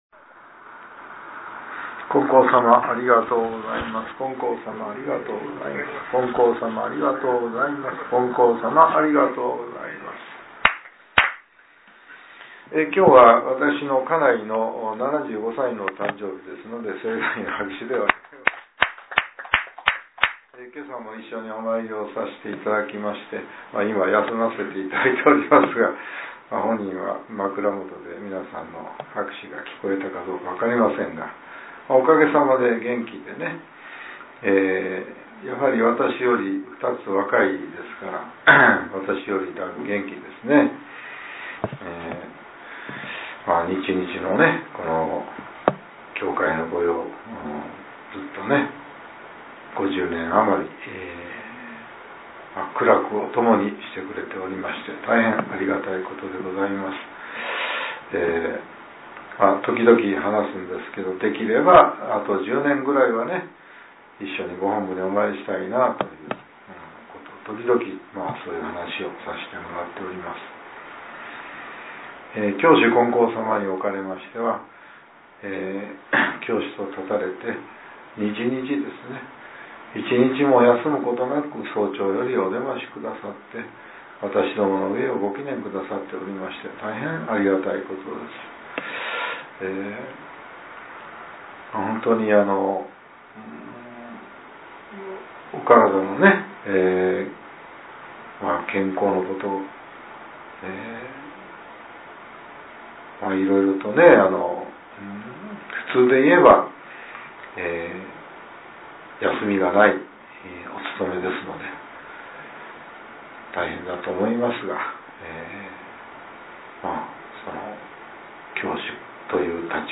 令和７年２月１２日（朝）のお話が、音声ブログとして更新されています。